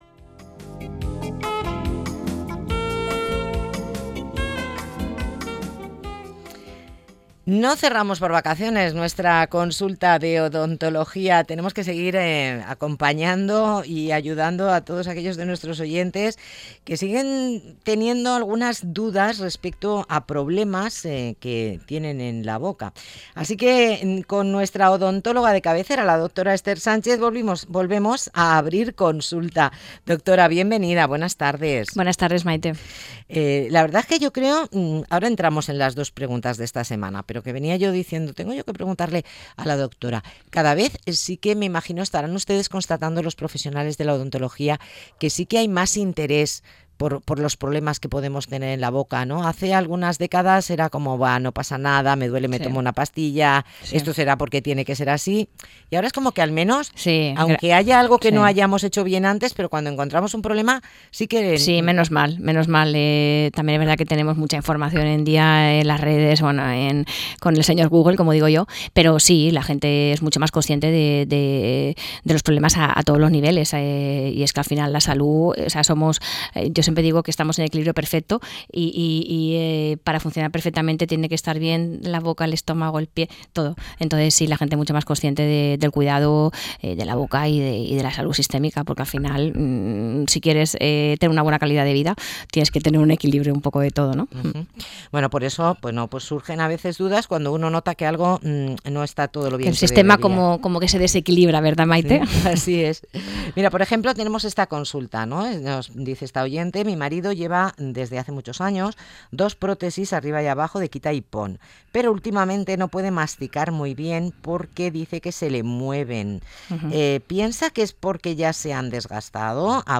durante su intervención en el programa «Más de uno Elche» de Onda Cero.